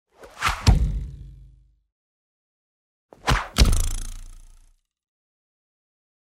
Звуки ножа
Звук вонзающегося ножа в дерево